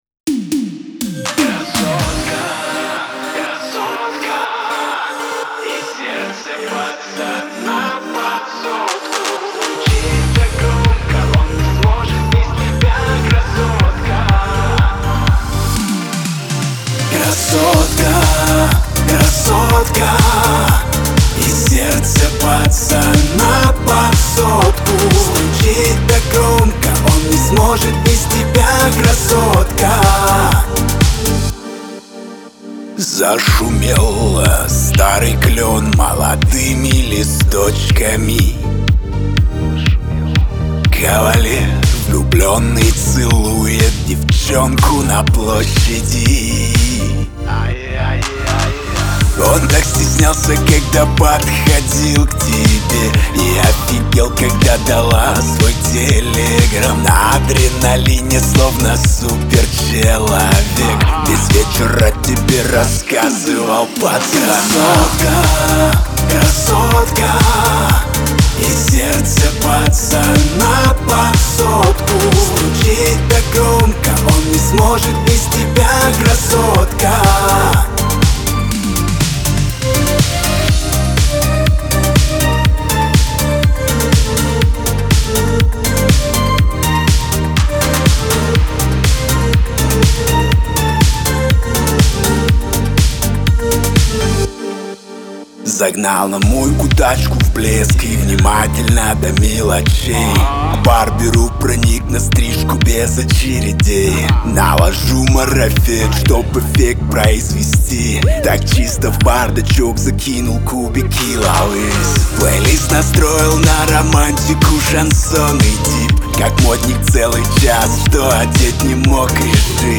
ХАУС-РЭП